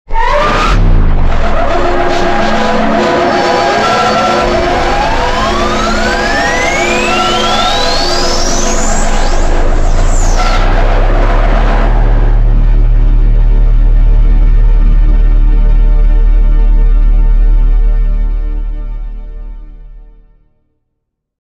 Vine Boom long/loud
sound-bomb-made-with-Voicemod-technology-timestrech-1.16x-1.mp3